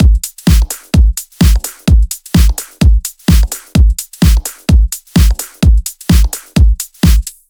VFH3 128BPM Wobble House Kit